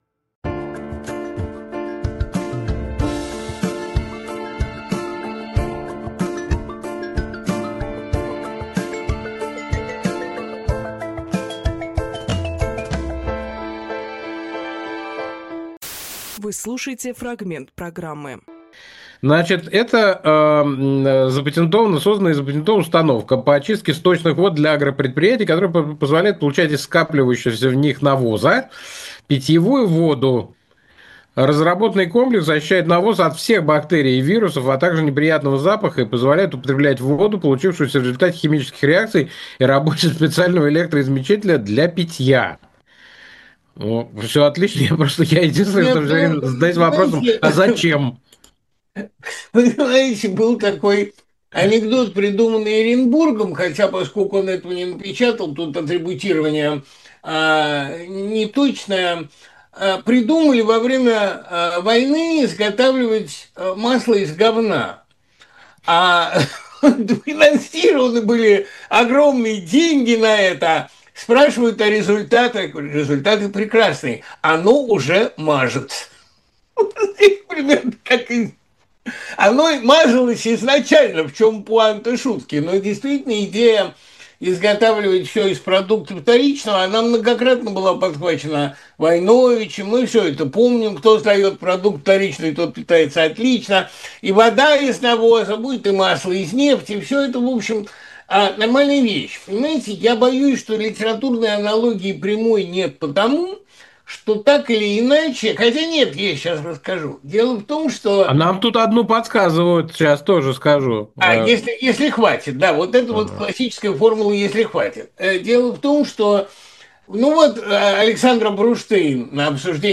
Фрагмент эфира от 13.07.25